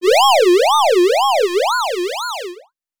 CartoonGamesSoundEffects